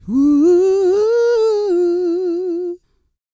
E-CROON 202.wav